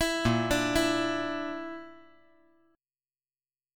BbMb5 Chord
Listen to BbMb5 strummed